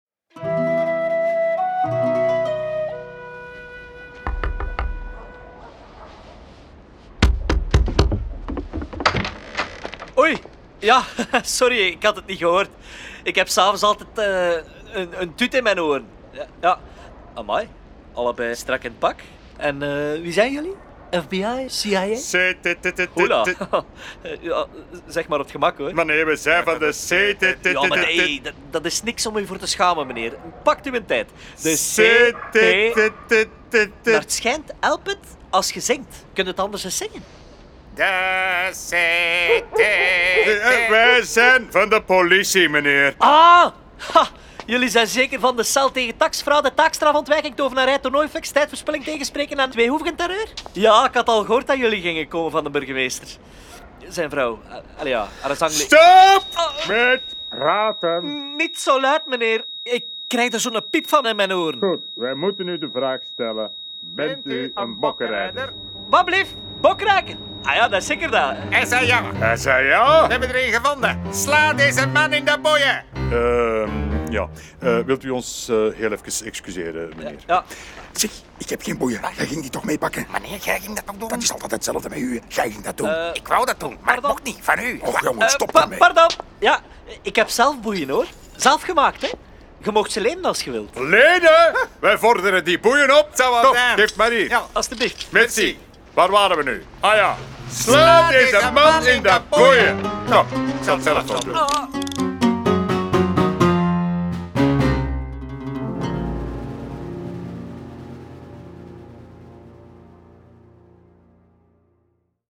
De Bokrokker is niet alleen een boek om te lezen, maar ook een hoorspel boordevol grappen en vrolijke liedjes.